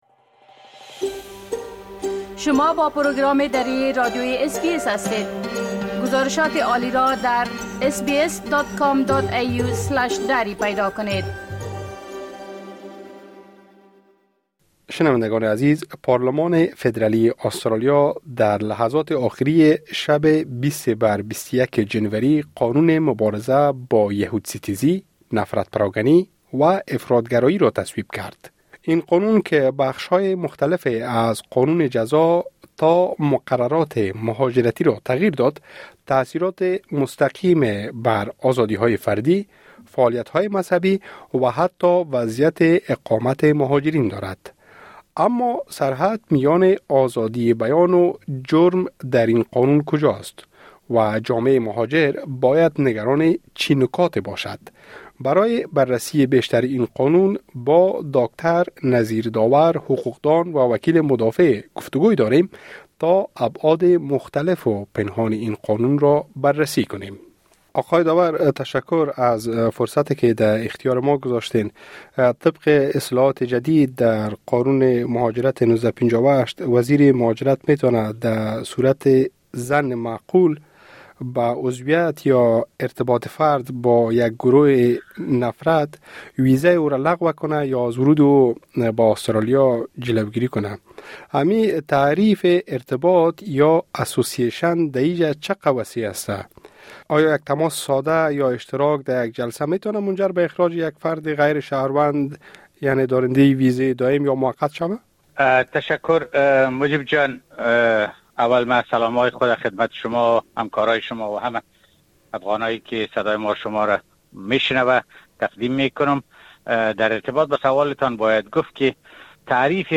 حقوق‌دان و وکیل مدافع گفتگوی داریم